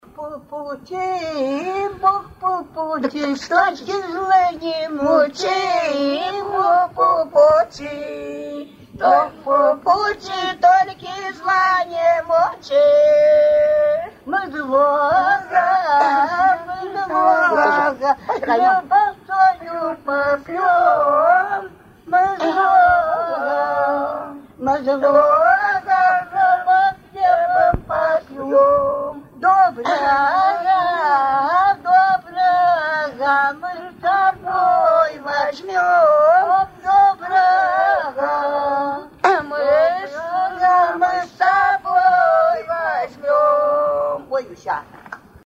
Свадебные обрядовые песни в традиции верховья Ловати
«Бог по пути» Поют, когда невесту отправляют домой к жениху исп.